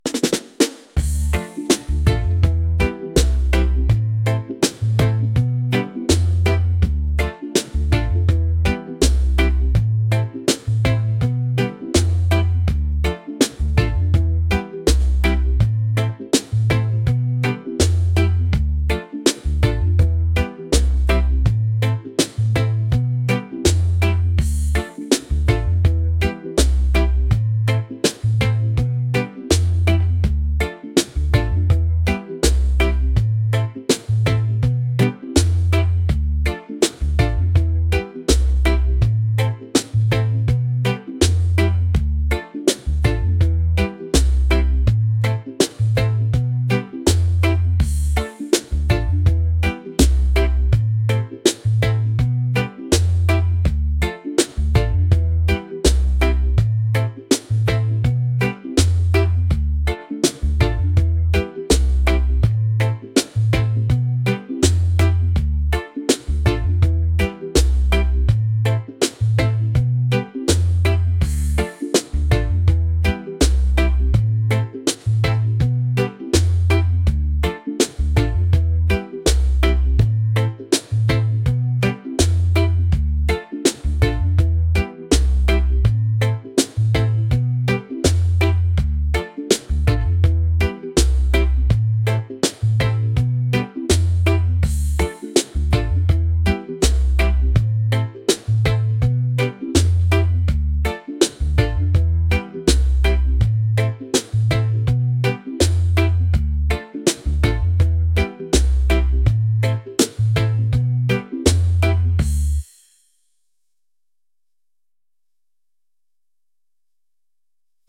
reggae | romantic | laid-back